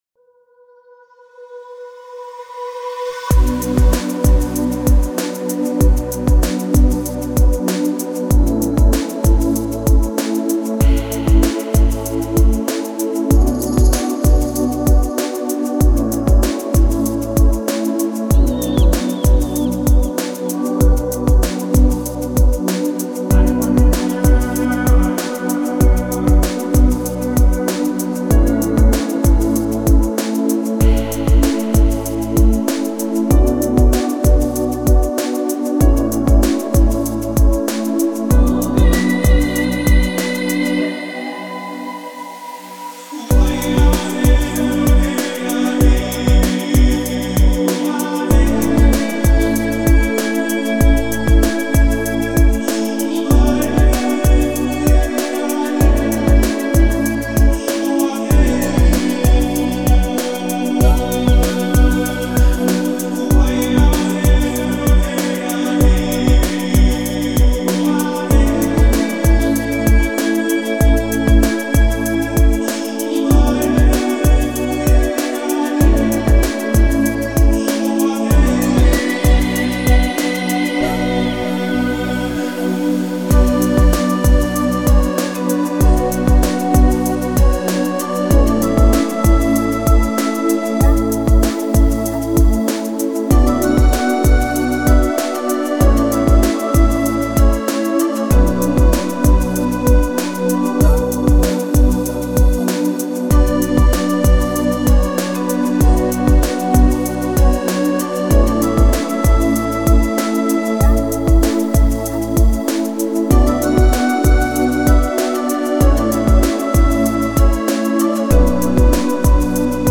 ریتمیک آرام
دیپ هاوس ریتمیک آرام موسیقی بی کلام